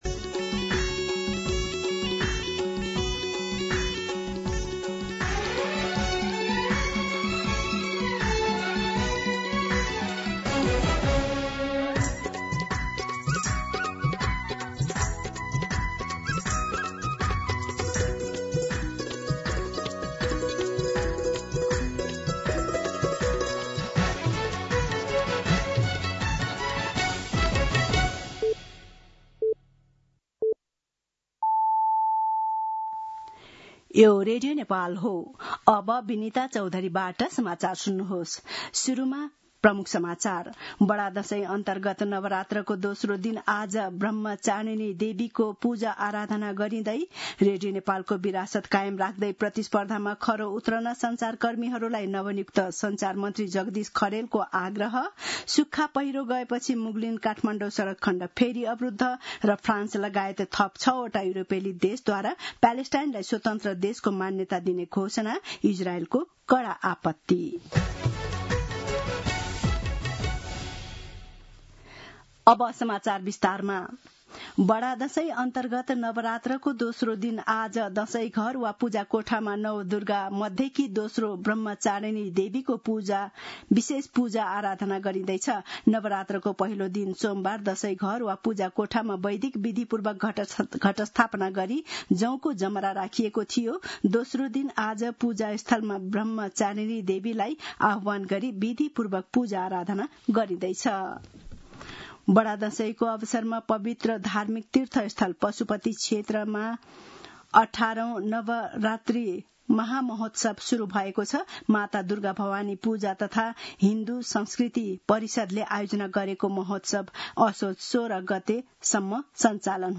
दिउँसो ३ बजेको नेपाली समाचार : ७ असोज , २०८२